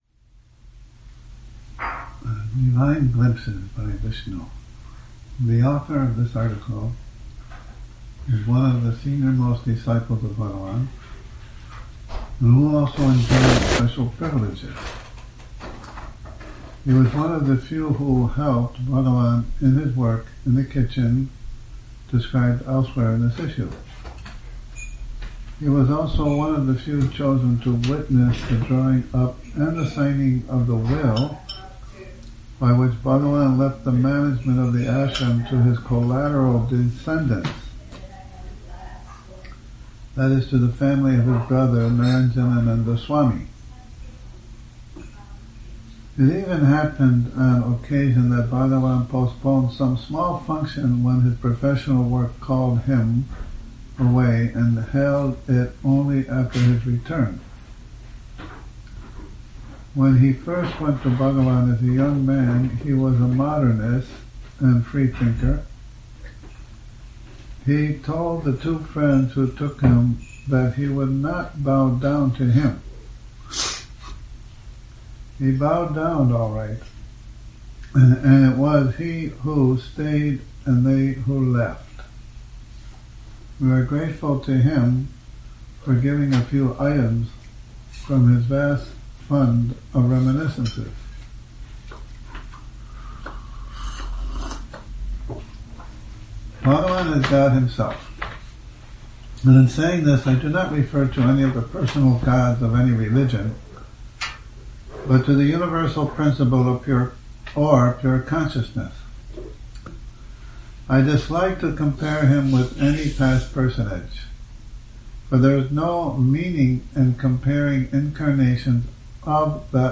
Morning Reading, 08 Nov 2019